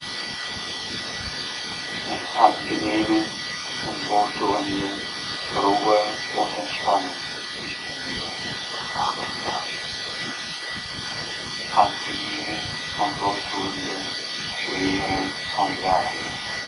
My own direct voice recording from 2003 - I swear it's genuine. An autogenic training induction tape in German was running in the background. Suddenly a whispering voice in English barged in. This is the unedited clip.
The voice is NOT on the autogenics tape, it seems to be coming from between me and the tape.